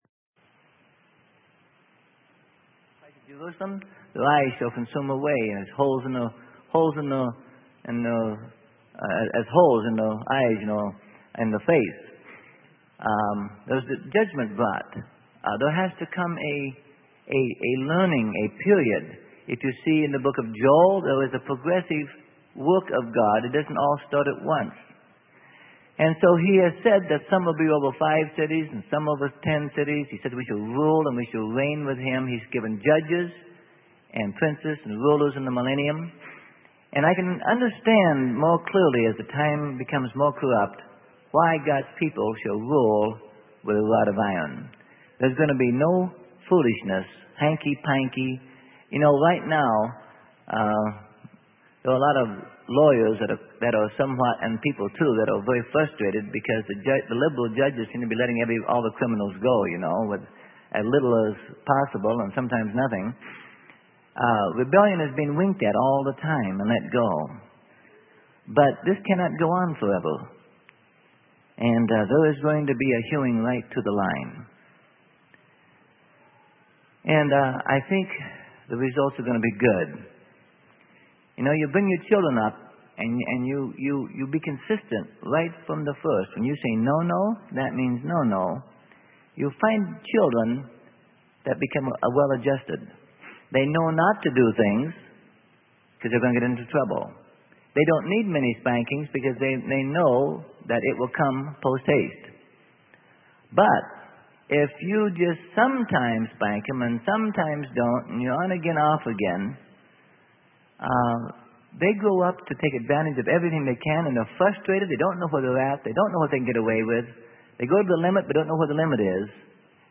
Sermon: The Millenium: What Will it Be Like - Part 2 - Freely Given Online Library